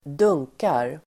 Uttal: [²d'ung:kar]